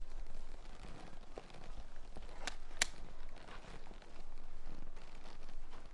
描述：这是一块皮带绑在马鞍上，收紧。
Tag: 拧紧 扭曲 皮革